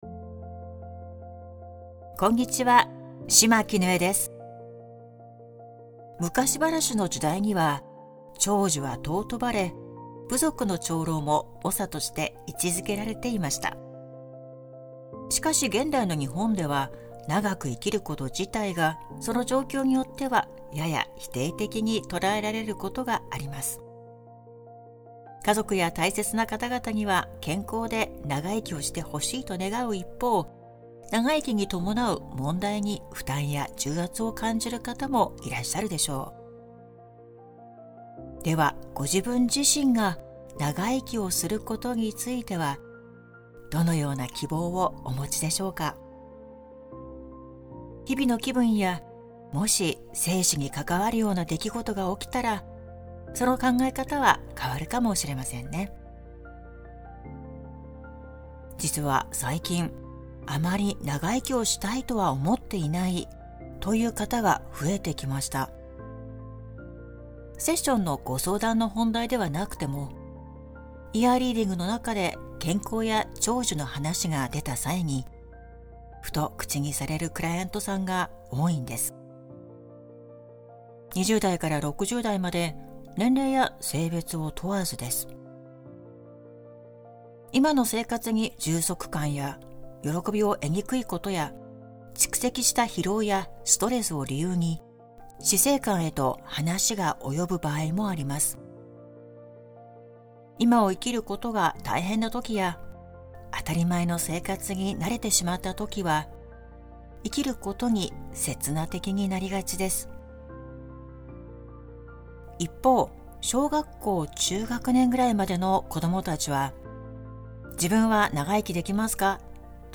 ※ [ 5:10 頃 ] 〈「生命力をヒーリング」〉誘導ワーク♪